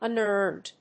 発音記号
• / `ʌnˈɚːnd(米国英語)
• / ʌˈnɜ:nd(英国英語)